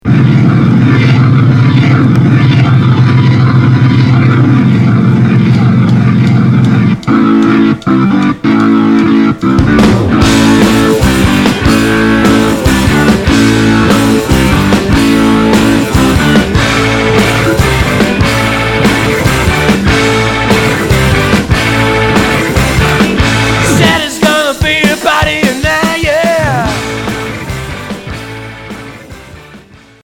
Garage Premier 45t retour à l'accueil